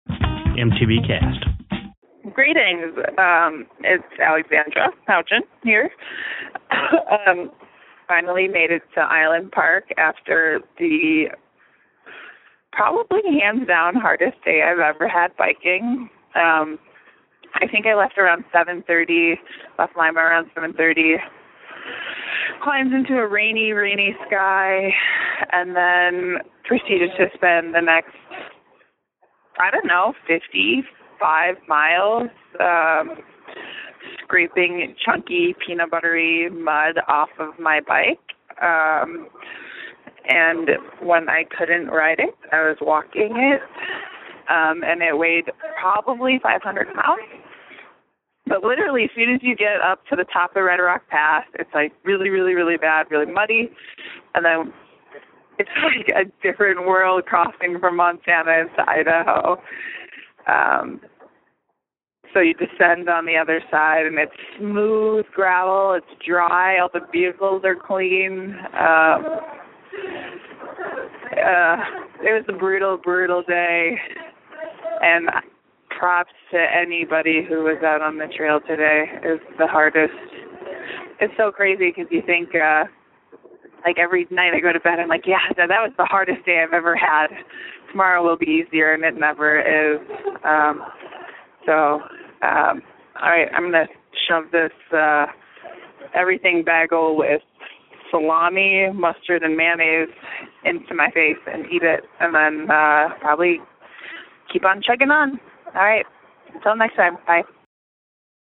Posted in Calls , TD18 Tagged bikepacking , cycling , MTBCast , TD18 , ultrasport permalink